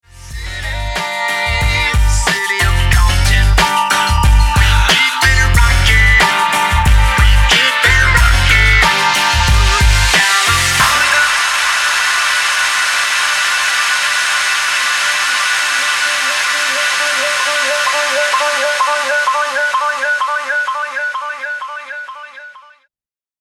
Hallo liebe Community :) Habe wiedermal ne Frage und zwar folgendes: Beim Sample, welches ich als Anhang beigefügt habe, erklingt ab 0:10 der Drop und dann erklingt dieser lange Hall der mehrere Sekunden bleibt.